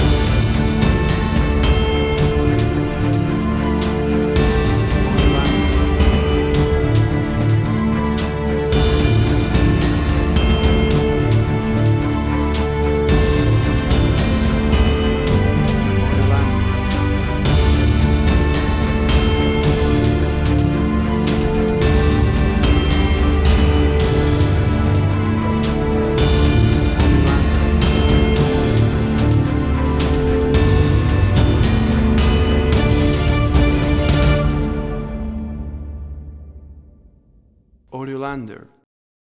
Tempo (BPM): 55